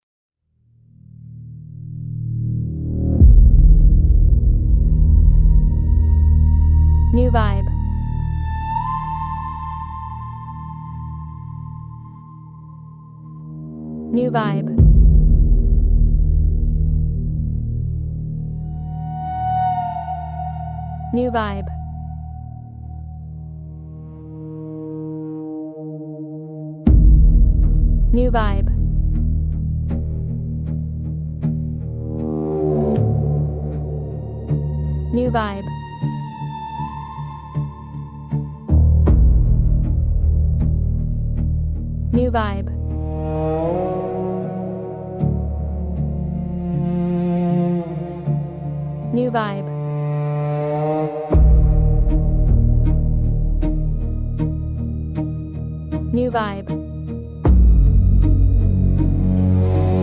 Genre: Mystery, Slow